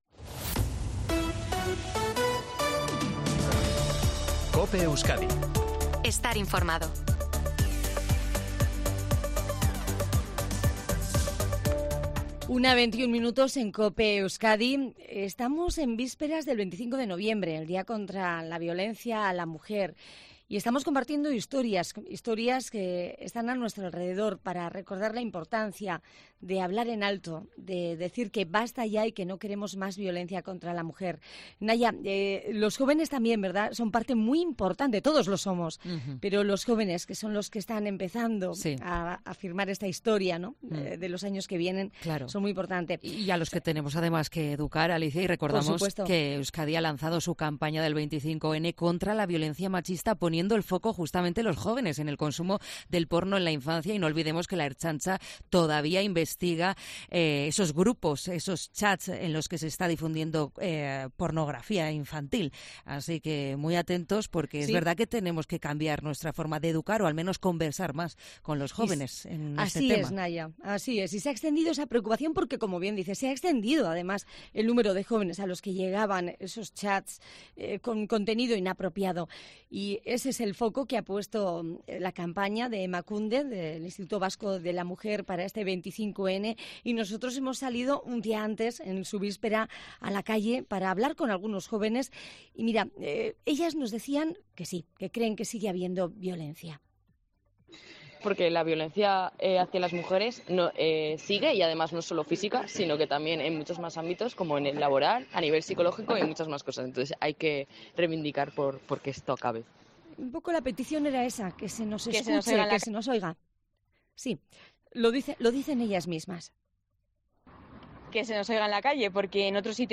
En COPE Euskadi hemos salido a la calle para conocer los pros y contras de una tendencia que ha llegado para quedarse